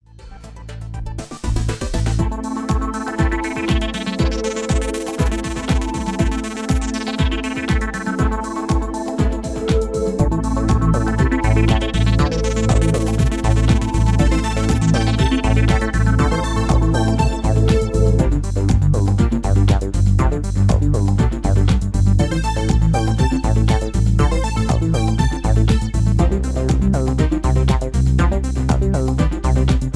Electro Ambient with feel of mystery